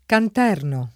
[ kant $ rno ]